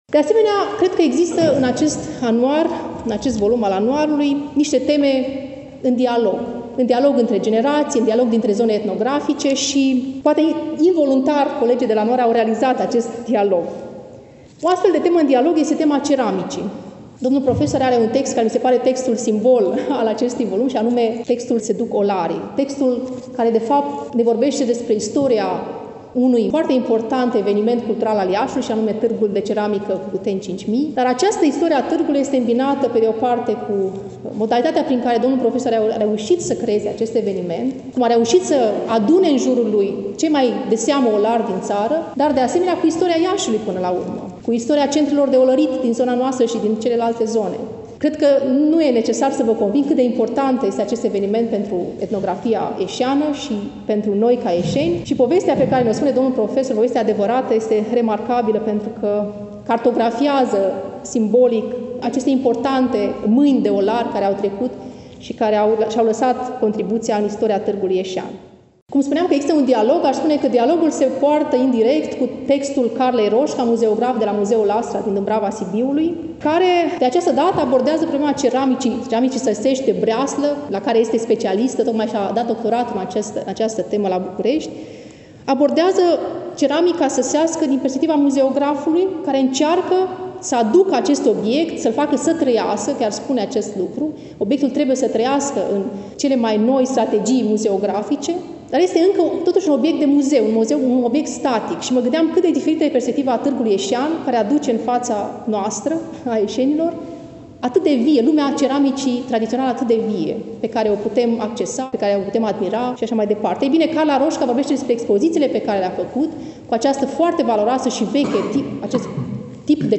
Stimați prieteni, vă reamintim că relatăm de la prezentarea „Anuarului Muzeului Etnografic al Moldovei”, Nr. XXII, lansat, nu demult, la Iași, în Sala „Petru Caraman” din incinta Muzeului Etnografic al Moldovei, Palatul Culturii.